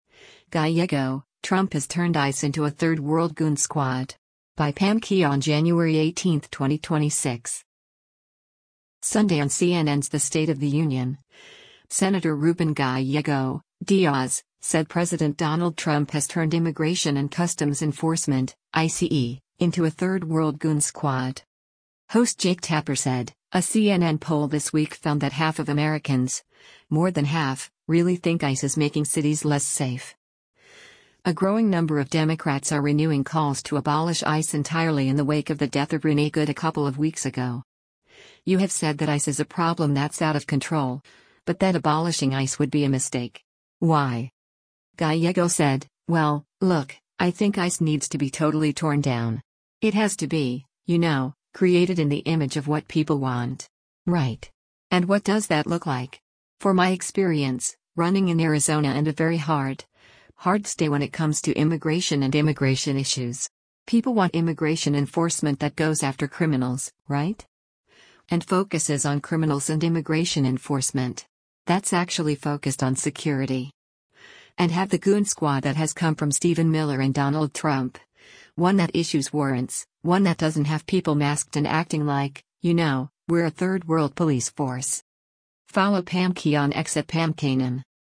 Sunday on CNN’s “The State of the Union,” Sen. Ruben Gallego (D-AZ) said President Donald Trump has turned Immigration and Customs Enforcement (ICE) into a third-world “goon squad.”